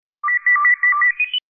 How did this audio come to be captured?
I'm looking for a better copy of the Babylon 5 door chime sound effect that I have attached to this toot. It's the only one I can find, but it's not good enough to use as a notification sound on my phone.